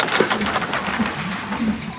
Windmill_Start.wav